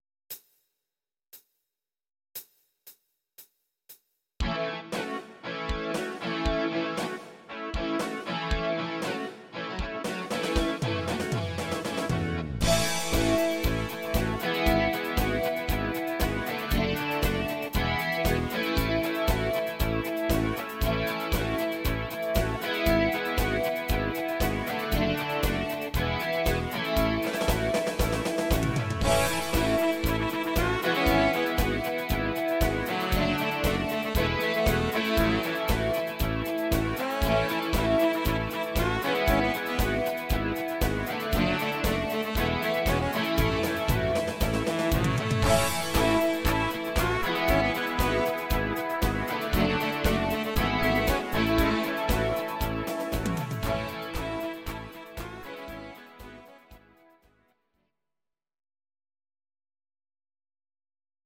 Audio Recordings based on Midi-files
Pop, Rock, 1970s